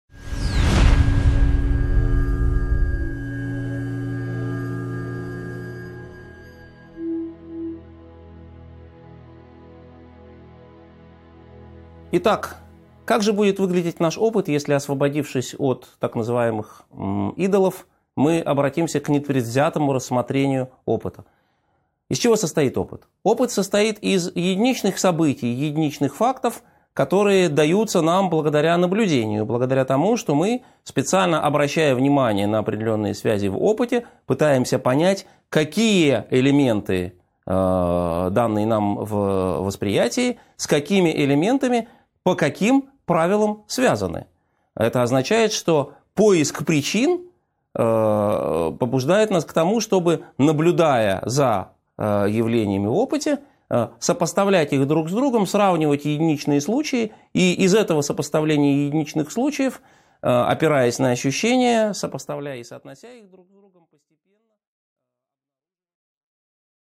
Аудиокнига 9.4 Методология эмпирического познания | Библиотека аудиокниг